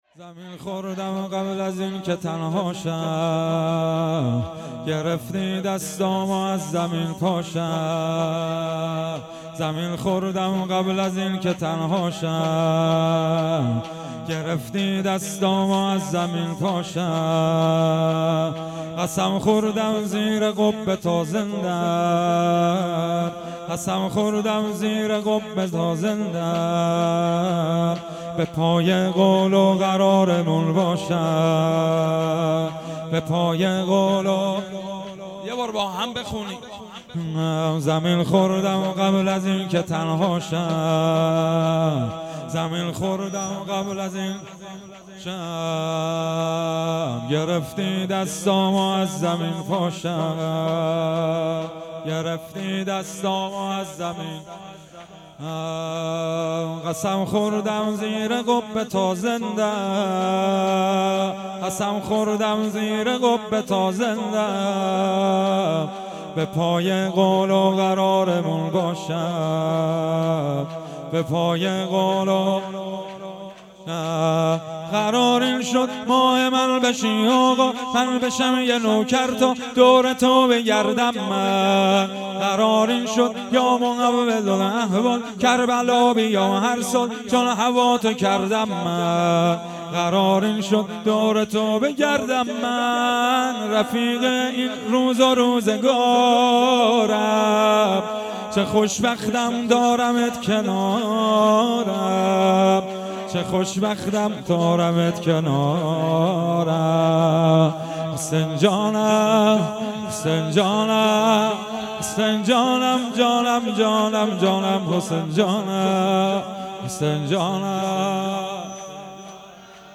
زمین خوردم قبل از اینکه تنها شم _ شور
شهادت حضرت معصومه سلام الله علیها